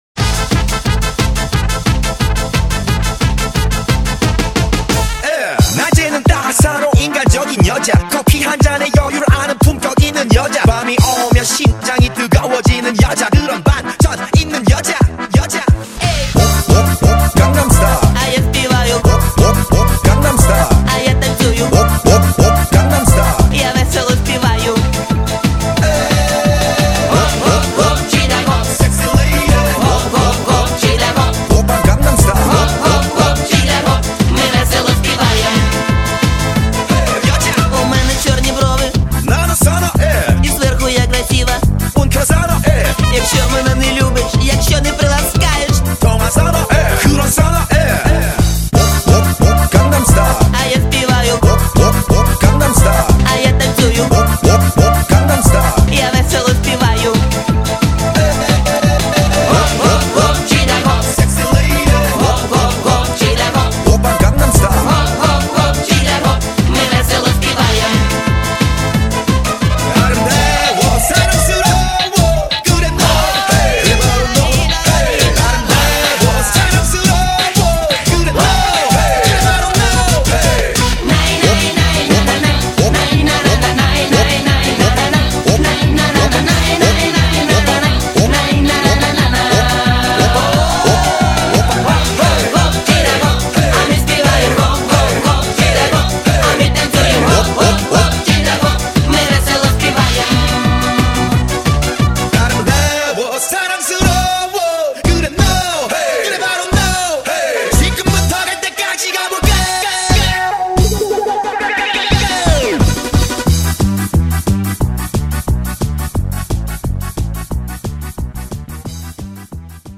Главная » Mp3 музыка » POP